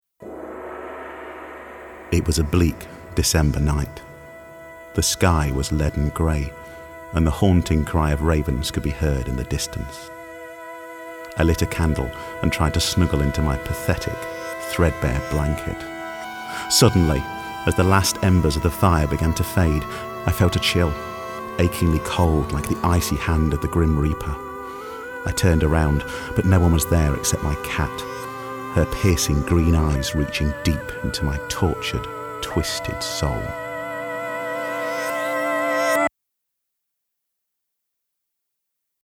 Horror